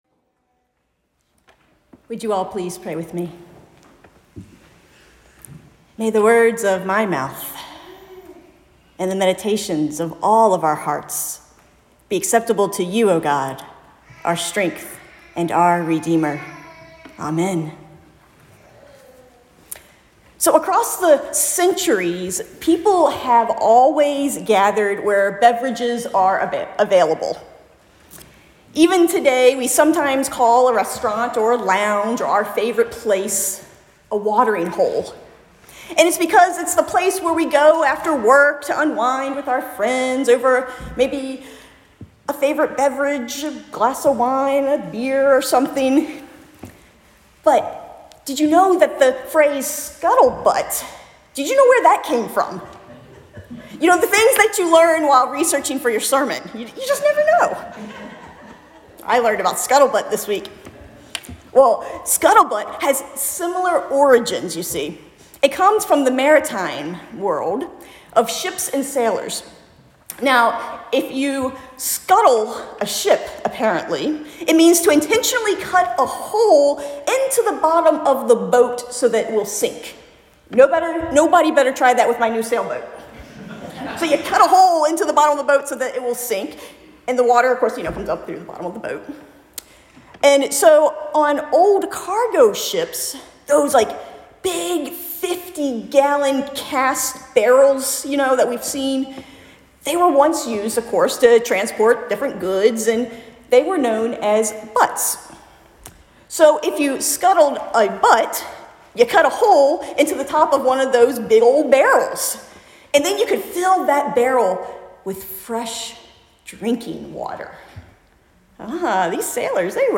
Weekly sermon podcasts from First Church Sandwich, UCC